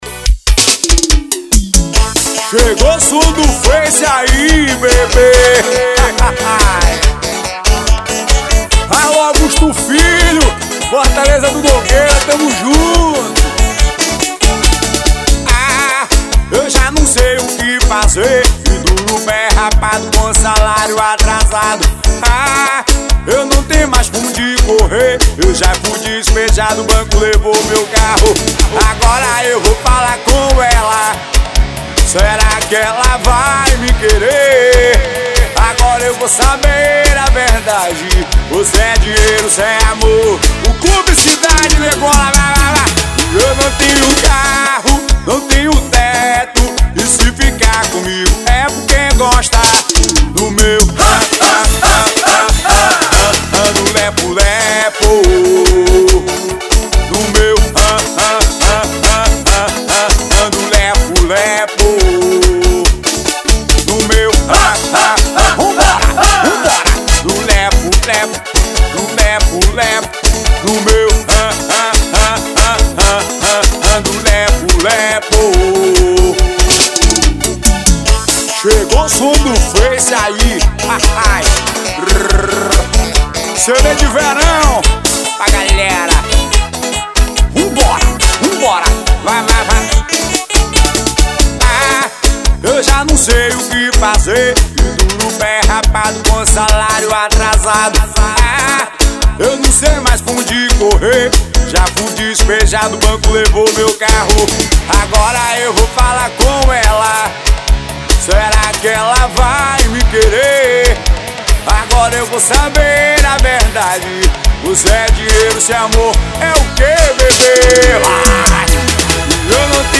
AO VIVO.